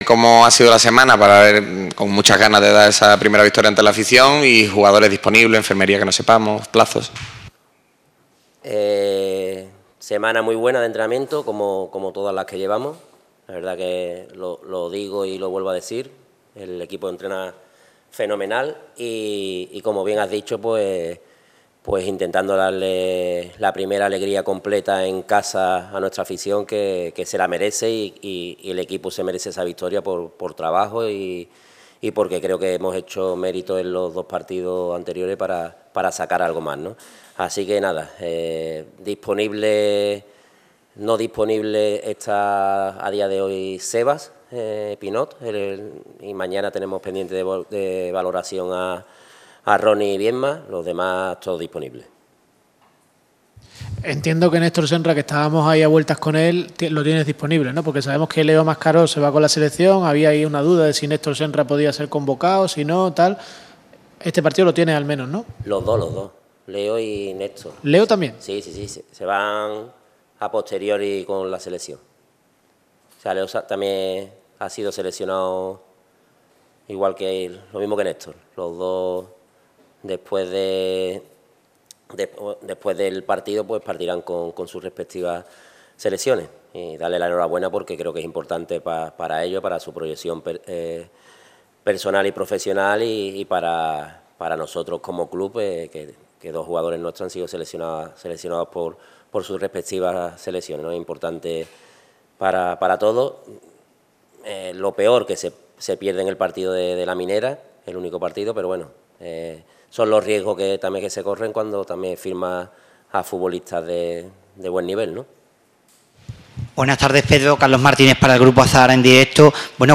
Puedes oír la rueda de prensa del técnico albiazul en este reproductor: